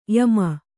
♪ yama